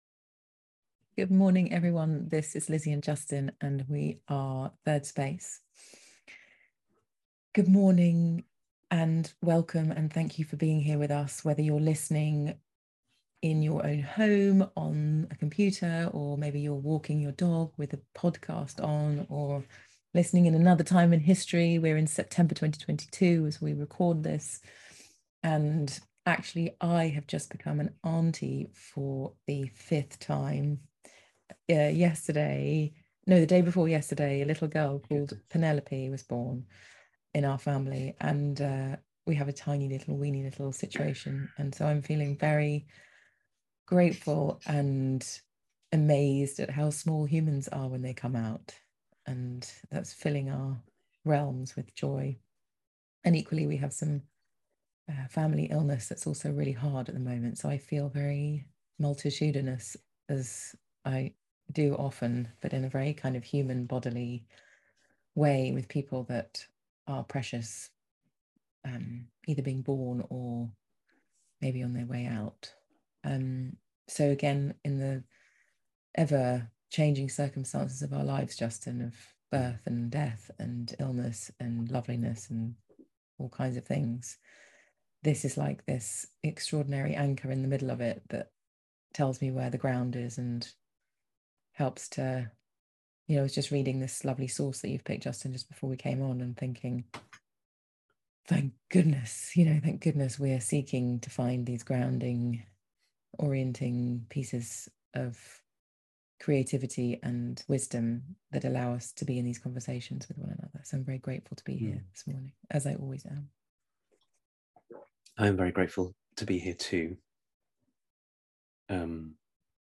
is a live 30 minute conversation